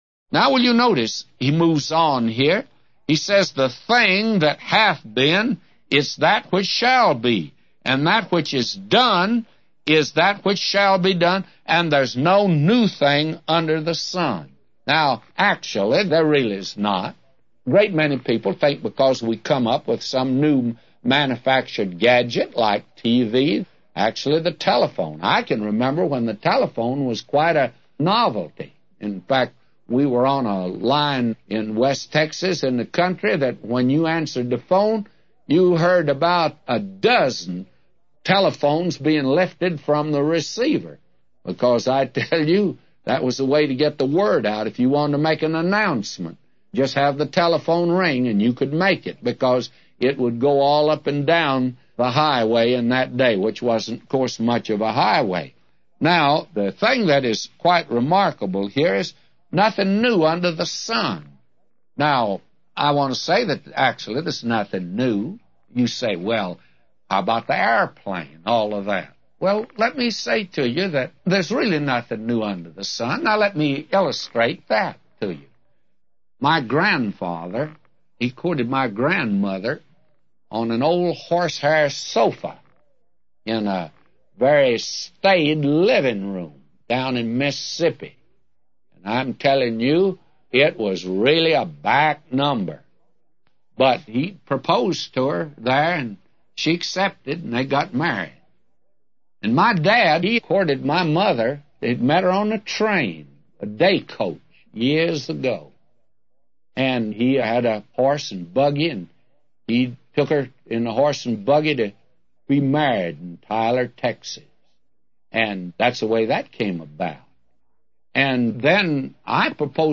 A Commentary By J Vernon MCgee For Ecclesiastes 1:9-999